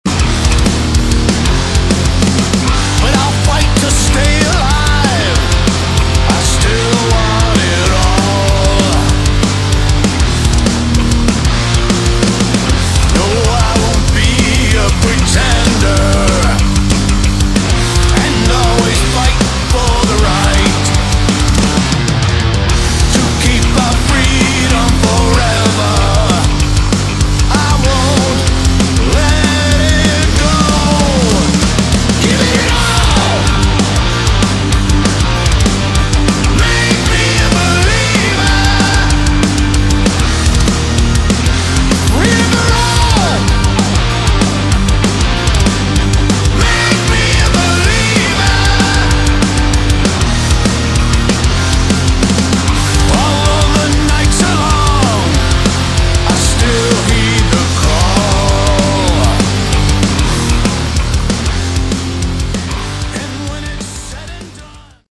Category: Melodic Metal
guitar, vocals
bass
drums